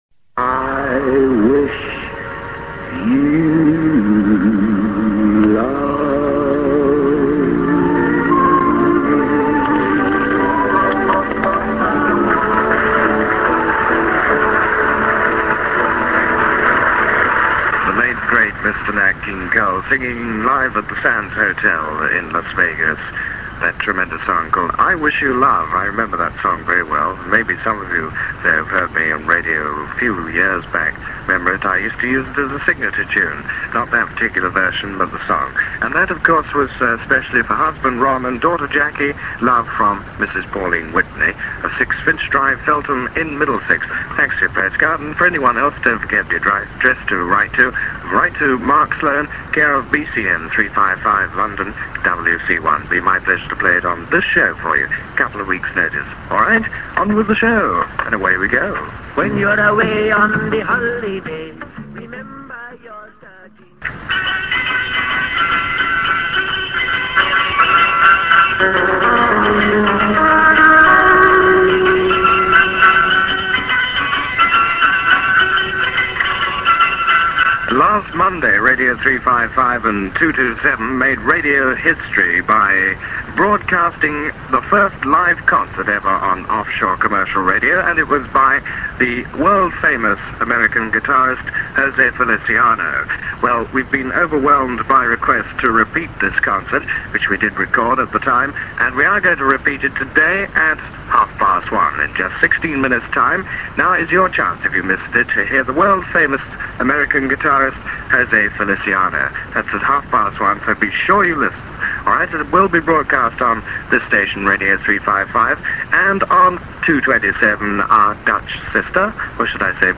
on the lunchtime request show on Radio 355, 8th July 1967